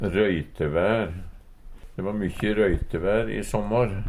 røytevær - Numedalsmål (en-US)